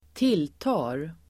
Uttal: [²t'il:ta:(ge)r]